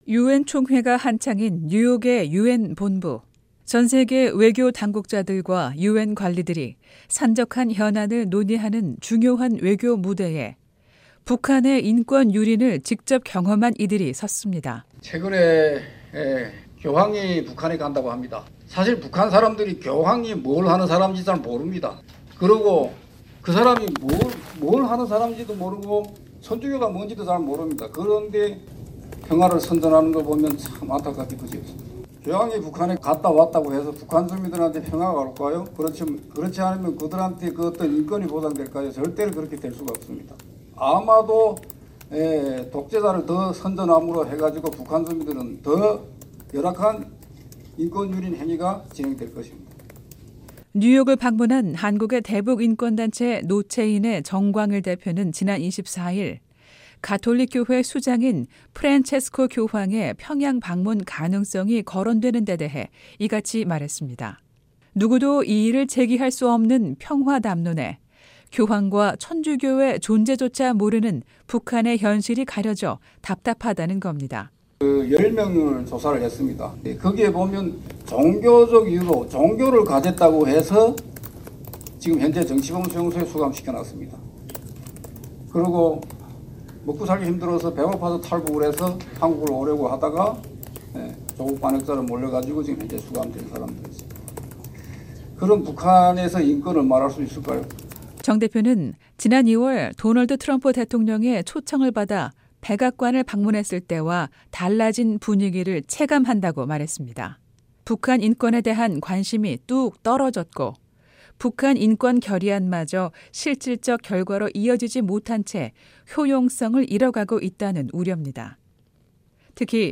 생생 라디오 매거진, 한 주 간 북한 관련 화제성 뉴스를 전해 드리는 ‘뉴스 풍경’ 시간입니다. 유엔본부에서 열린 북한인권토론회에서 한국 내 탈북민들이 북한인권에 대해 증언했습니다.